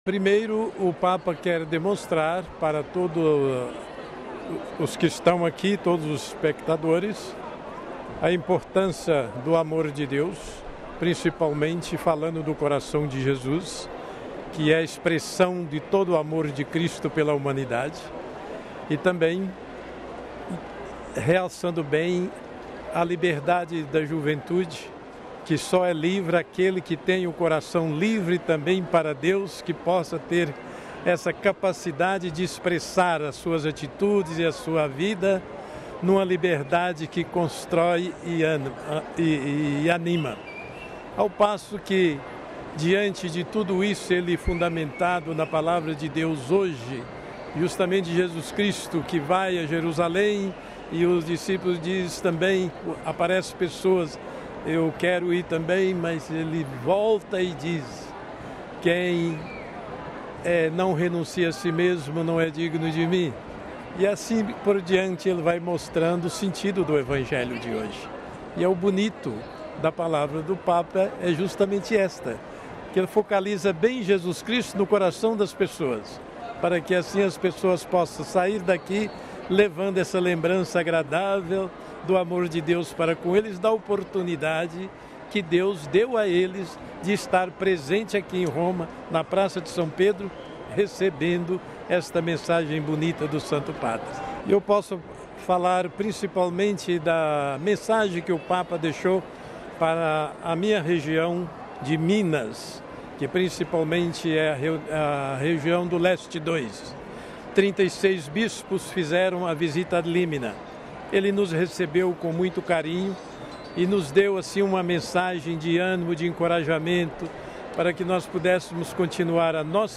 Cidade do Vaticano, 27 jun (RV) - Presente na Praça São Pedro nesta manhã durante a oração do Angelus, o Arcebispo de Pouso Alegre, MG. Dom Ricardo Pedro, que conversou com a Rádio Vaticano.